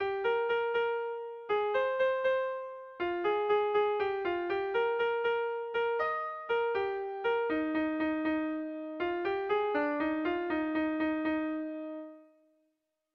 Bertso melodies - View details   To know more about this section
Irrizkoa
--8A / 10A / 9A / 10A
ABDE